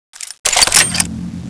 Index of /server/sound/weapons/tfa_cso/laserfist
shootb_shoot.wav